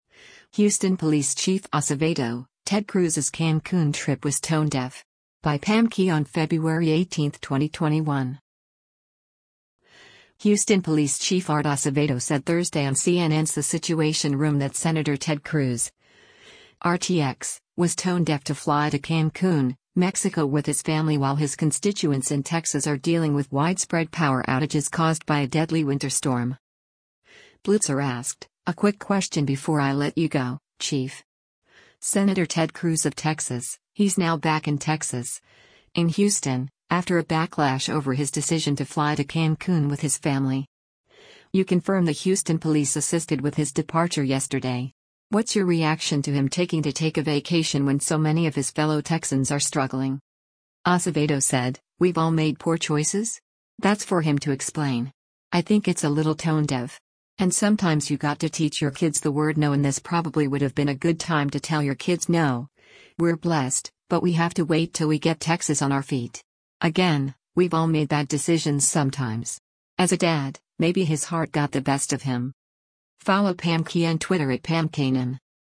Houston Police Chief Art Acevedo said Thursday on CNN’s “The Situation Room” that Sen. Ted Cruz (R-TX) was “tone deaf” to fly to Cancun, Mexico with his family while his constituents in Texas are dealing with widespread power outages caused by a deadly winter storm.